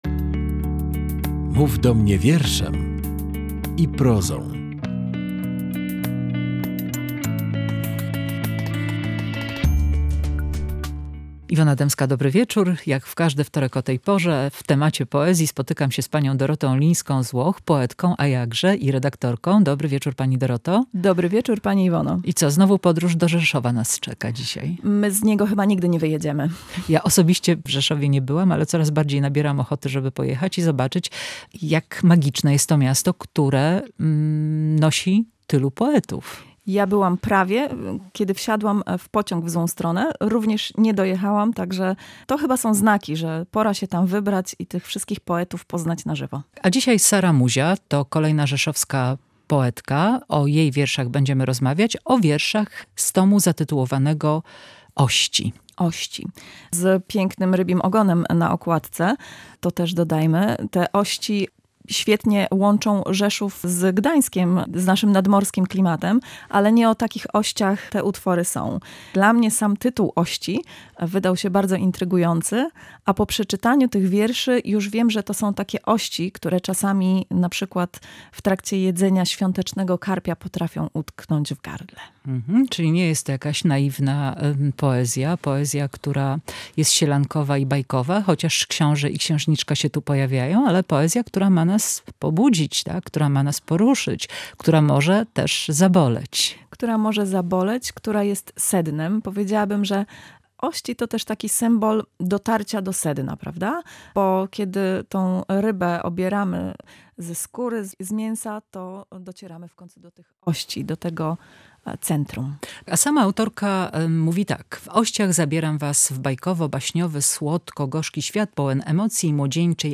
poezja